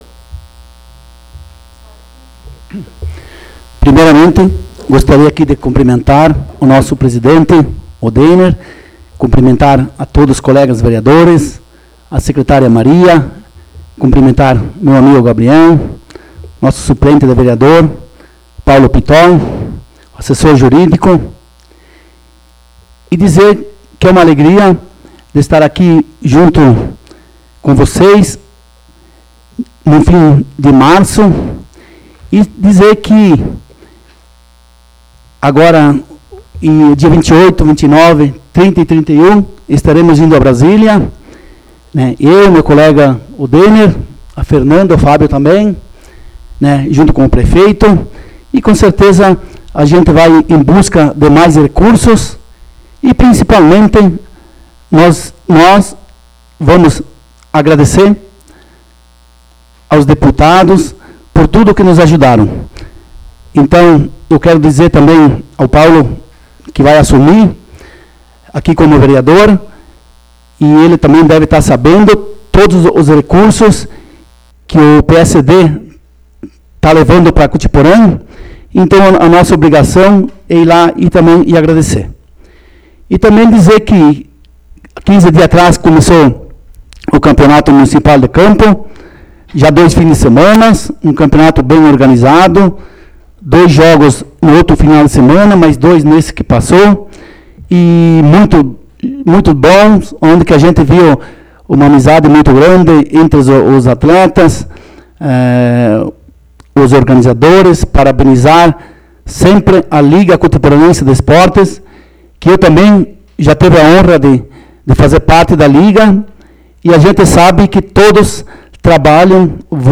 4ª Sessão Ordinária 2022
áudio da sessão